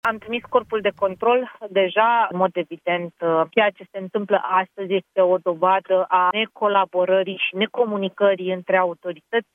„Am trimis Corpul de Control deja. În mod evident, ceea ce se întâmplă astăzi este o dovadă a necolaborării și necomunicării între autorități”, a spus ministra Buzoianu.